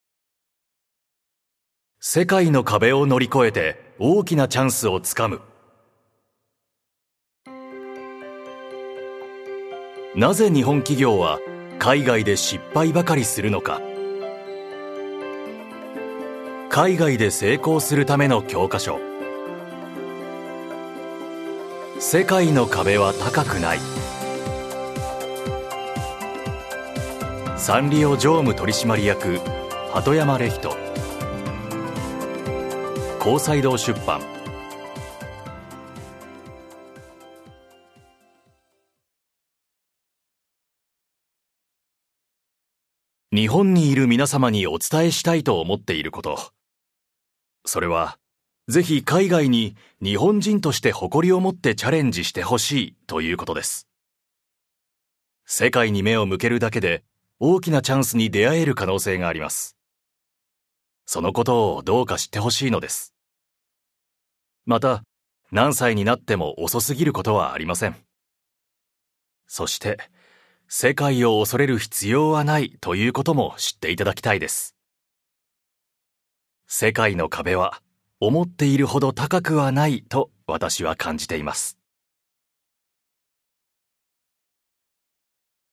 [オーディオブック] 世界の壁は高くない